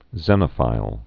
(zĕnə-fīl, zēnə-)